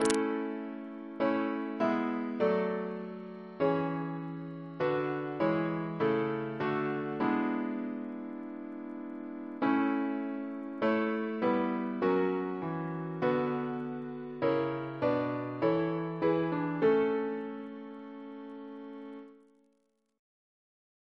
Double chant in A Composer: Alan Gray (1855-1935) Reference psalters: RSCM: 62